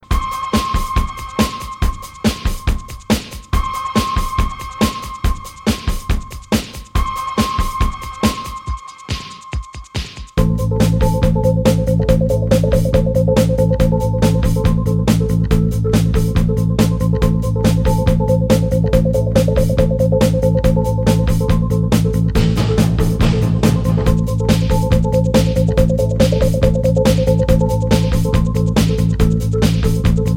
Disco Electronix House Funk Ambient